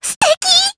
Rehartna-Vox_Happy4_jp.wav